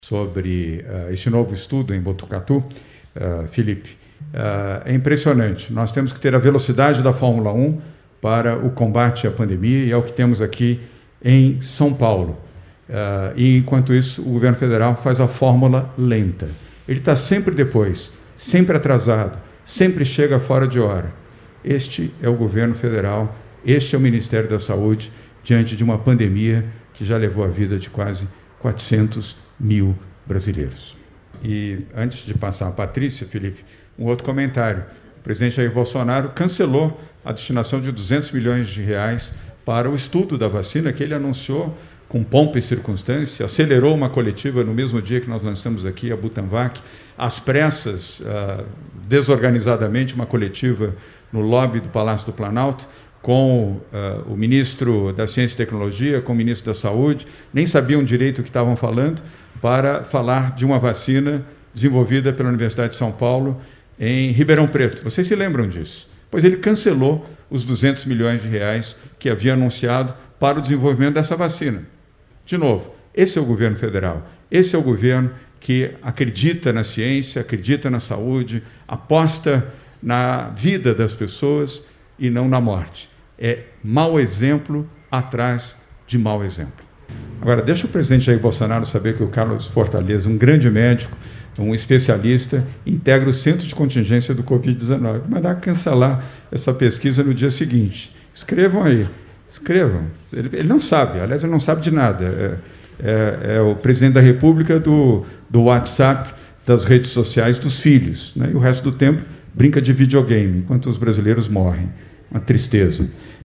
O governador respondeu a pergunta de um jornalista, durante a coletiva de imprensa no horário do almoço e preferiu tecer críticas ao governo federal do que comentar propriamente do estudo em uma cidade paulista.